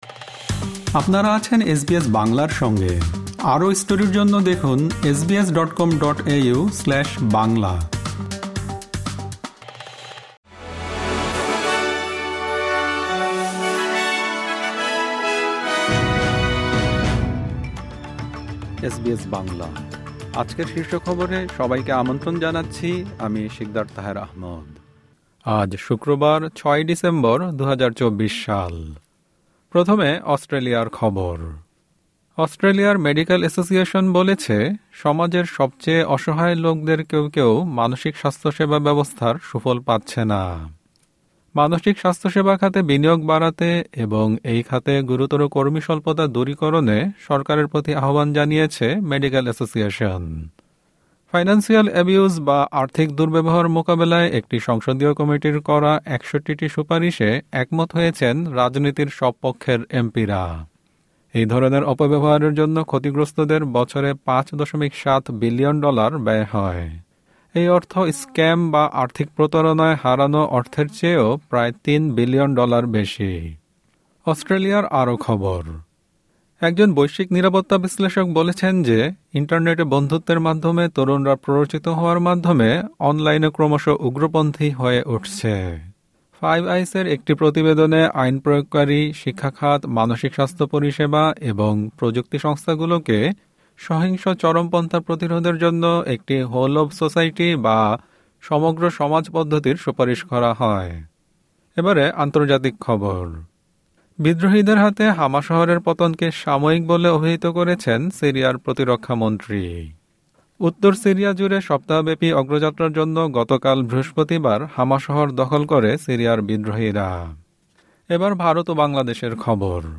এসবিএস বাংলা শীর্ষ খবর: ৬ ডিসেম্বর, ২০২৪